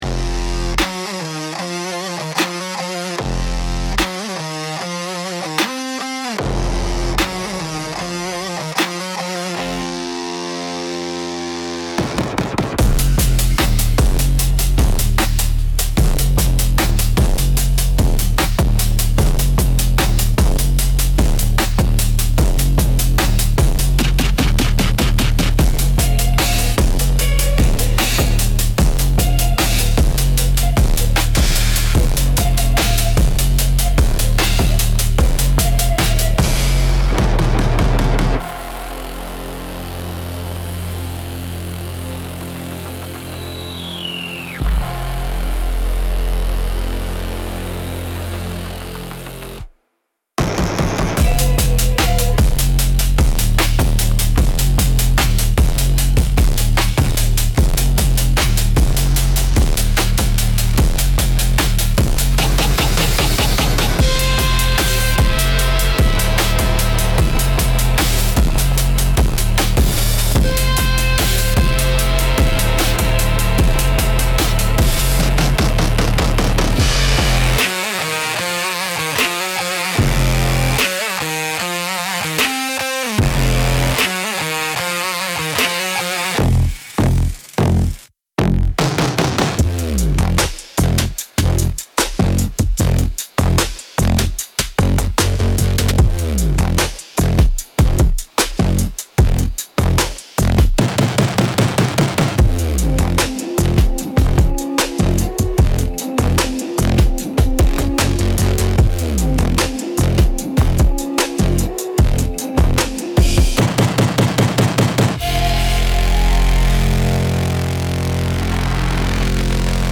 Instrumental - Cinematic Southern Gothic x 808 Bass